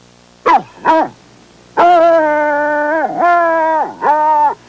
Bloodhound Sound Effect
bloodhound.mp3